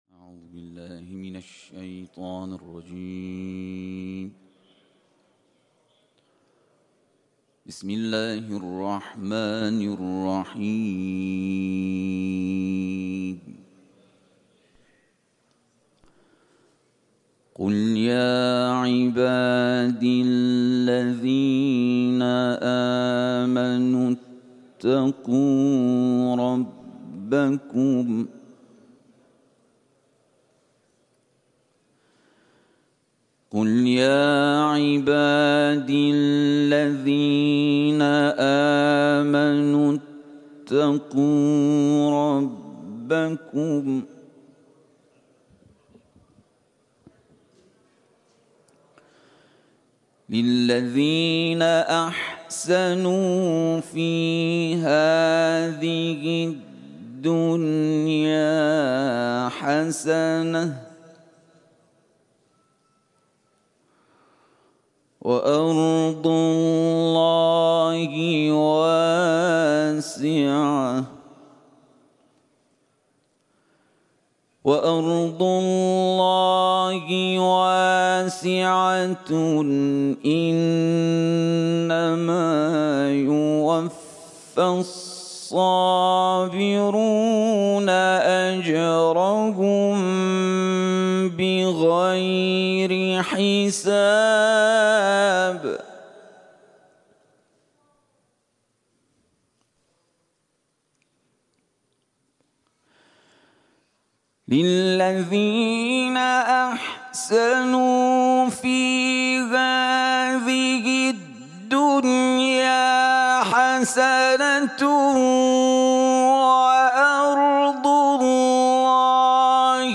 Etiketler: Kuran tilaveti ، İranlı kâri ، Zümer suresi